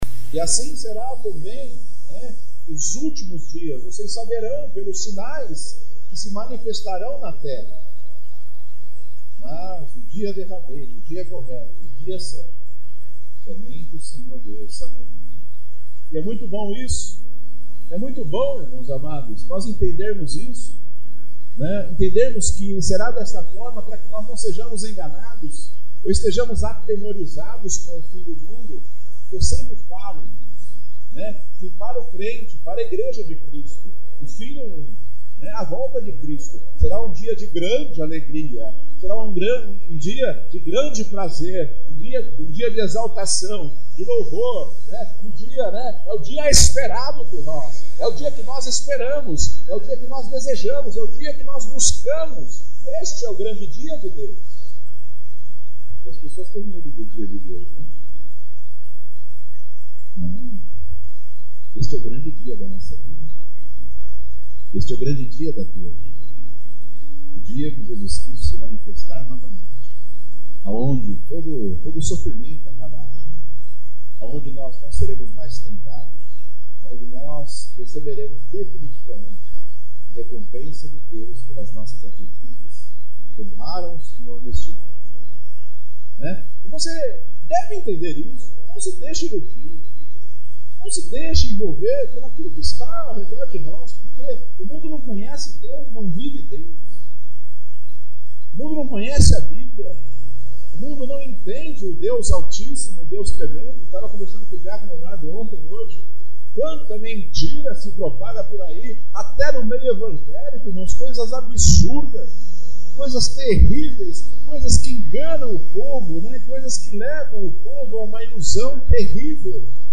Mateus 24:34-44;50;25:12-13;28-30;46(Áudio com baixa qualidade)